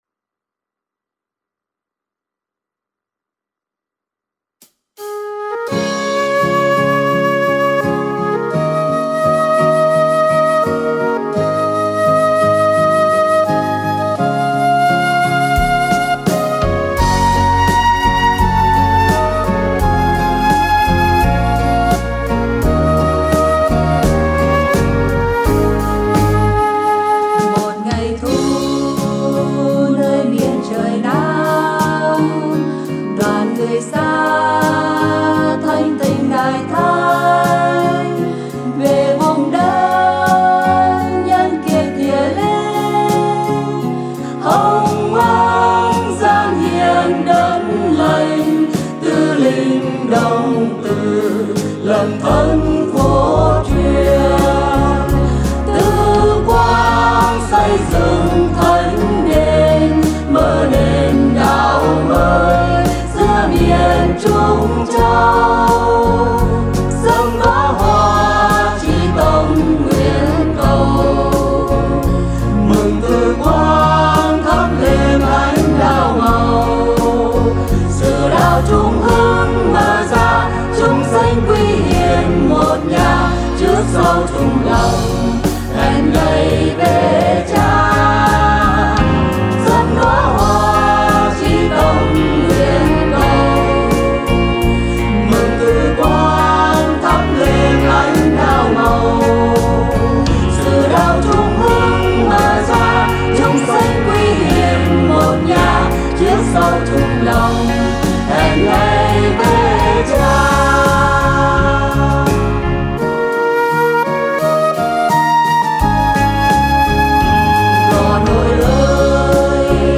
Thể Loại Đạo Ca
Tốp (C#)